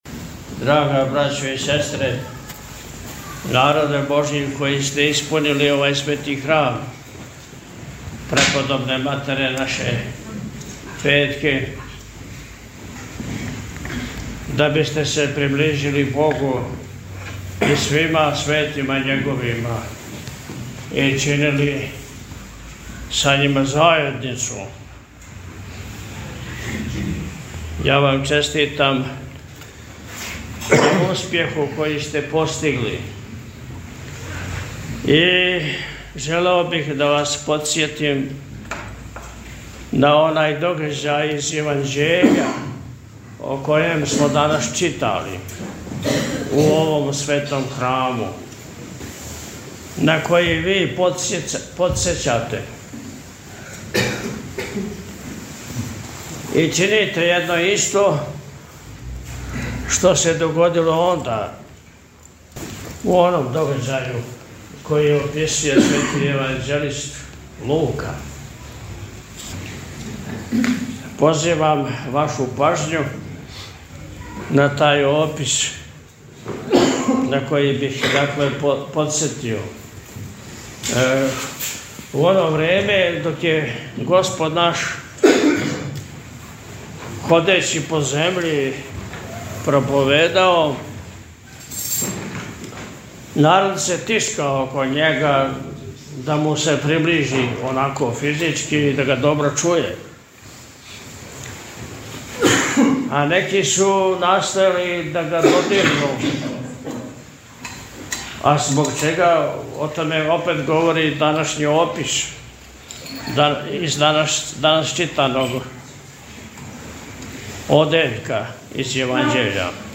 Бесједа-владика-Света-Петка-8.-децембар.mp3